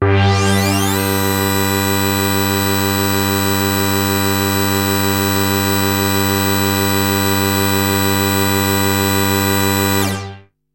标签： FSharp3 MIDI音符-55 Korg的 - 单 - 保利 合成 单注 多重采样
声道立体声